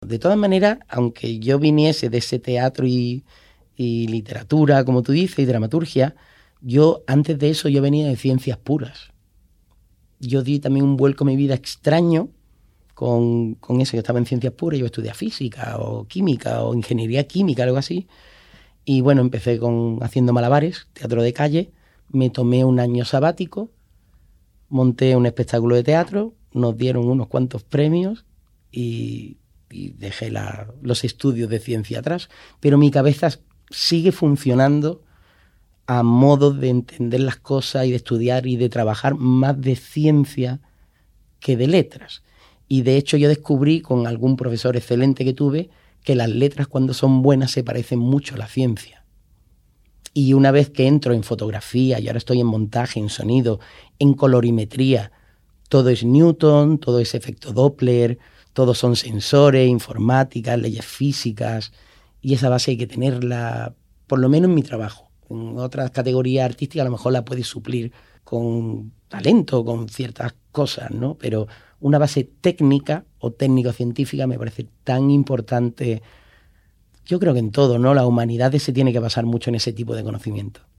con convencimiento formato MP3 audio(1,54 MB).